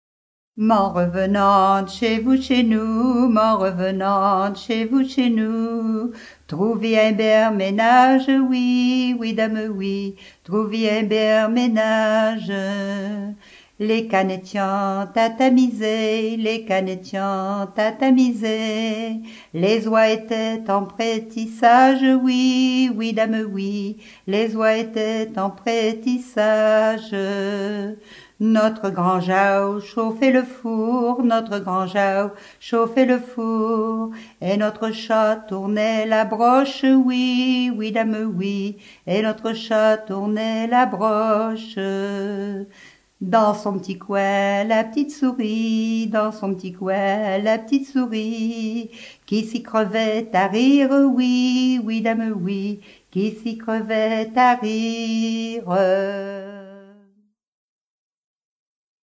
Catégorie Pièce musicale éditée